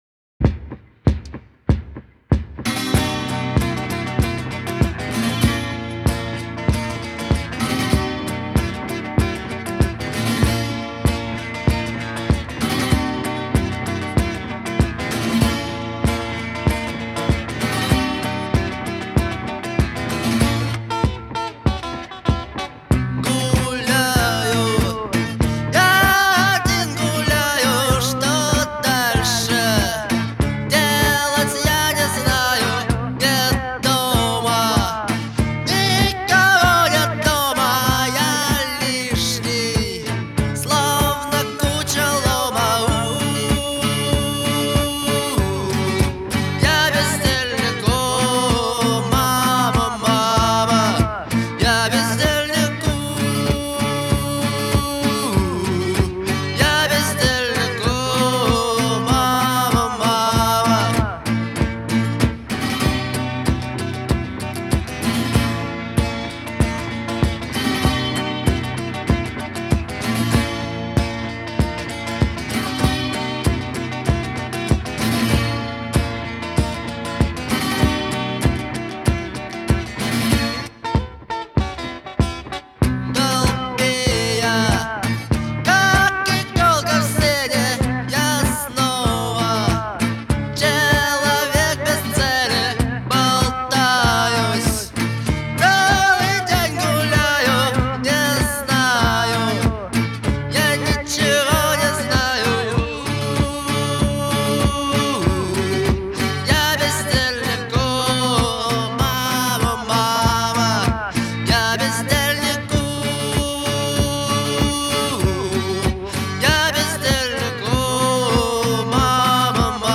это яркий пример русского рок-музыки 80-х